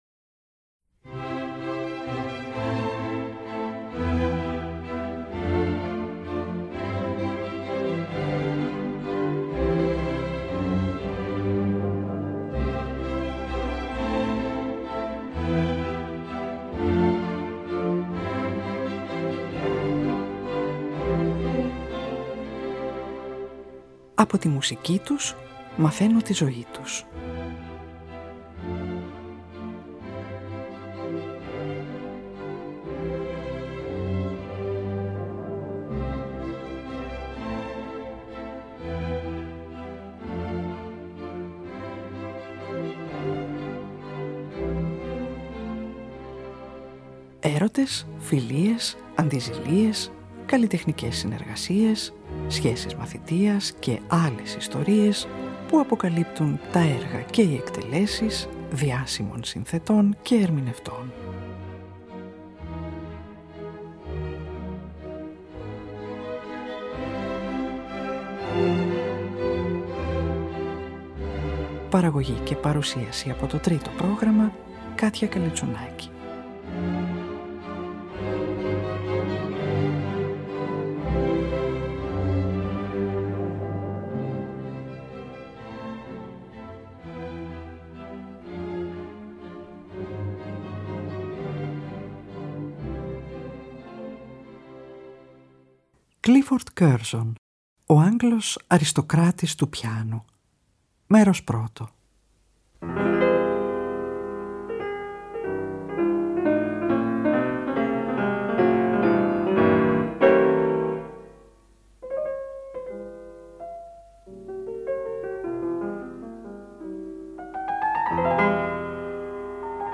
Σήμερα ο Sir Clifford Curzon ακούγεται με τη Συμφωνική Ορχήστρα του Λονδίνου υπο τον István Kertész και με την Αγγλική Ορχήστρα Δωματίου υπο τον Benjamin Britten στο Κοντσέρτο αρ 26 Κ537 του Mozart, το οποίο είχε πρωτοπαίξει 19 ετών στο Λονδίνο το 1926 με τον Thomas Beecham, και σε δύο μέρη από τα Κοντσέρτα αρ 24 Κ491 και αρ. 27 Κ595 του ίδιου συνθέτη.